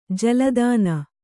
♪ jala dāna